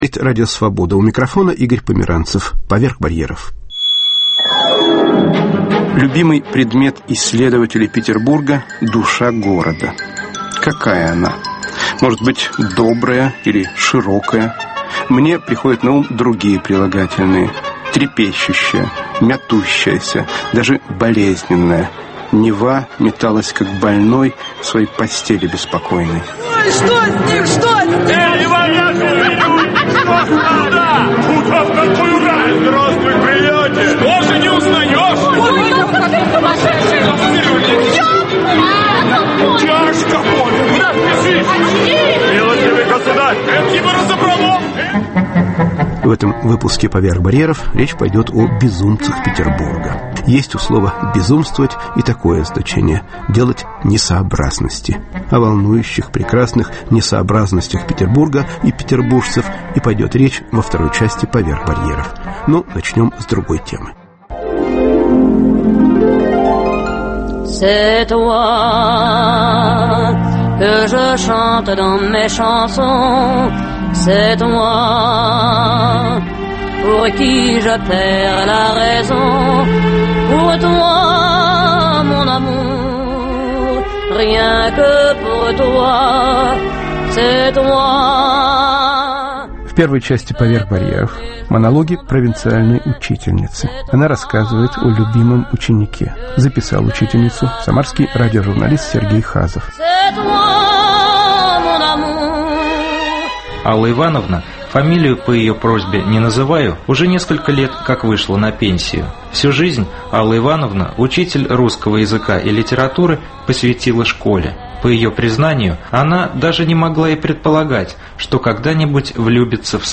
"Безумцы Петербурга" (образ города) Передача с участием петербургских деятелей культуры, таксистов, пенсионеров, городских сумасшедших.